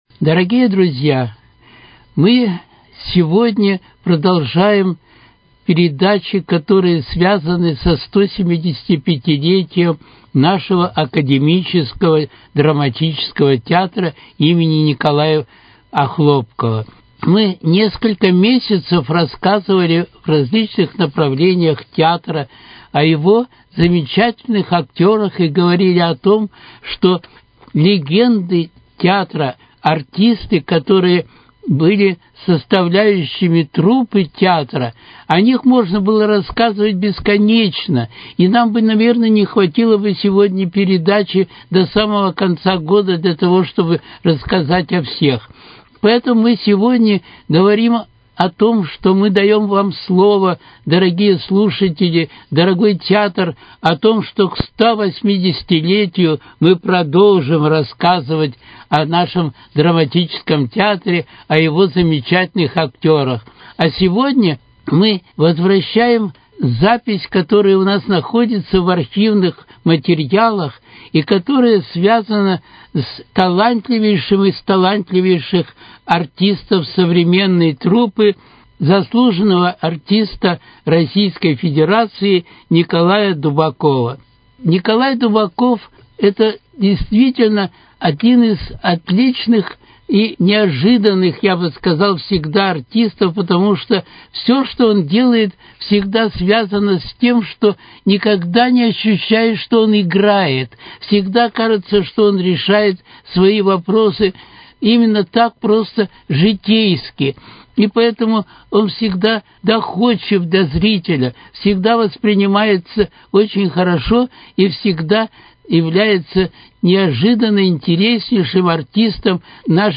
В этом выпуске беседа